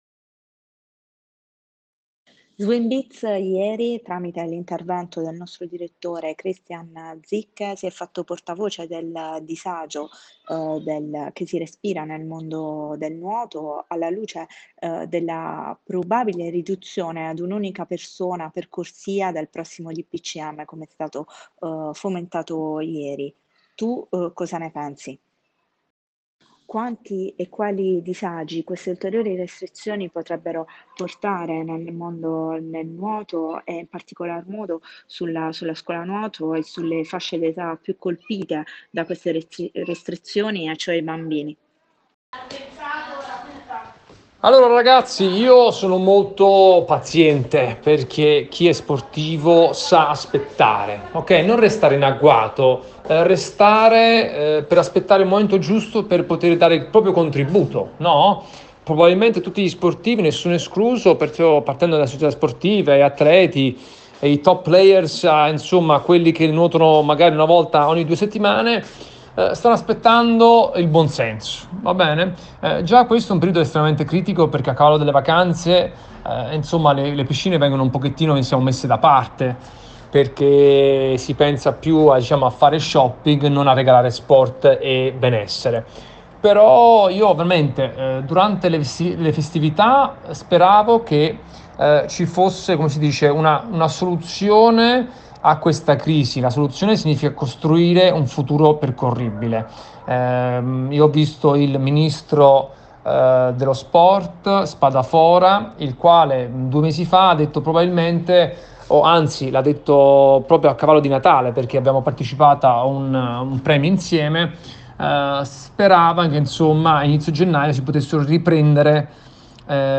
ha intervistato Massimiliano Rosolino